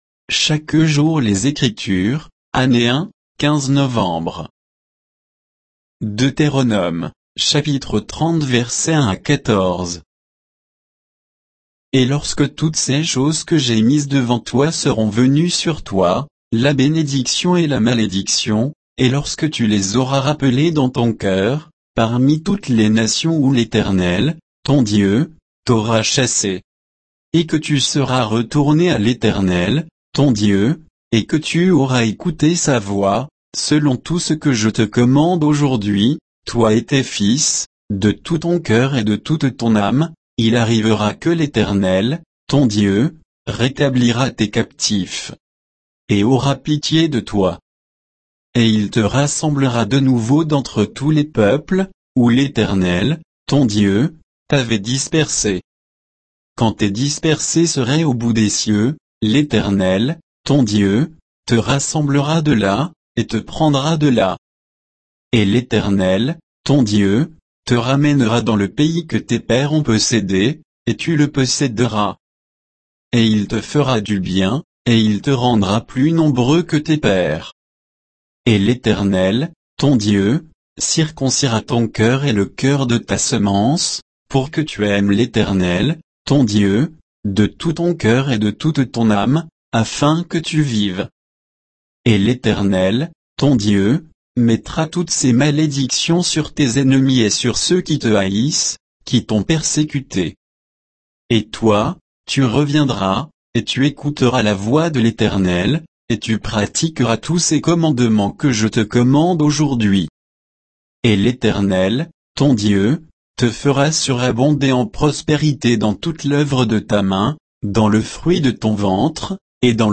Méditation quoditienne de Chaque jour les Écritures sur Deutéronome 30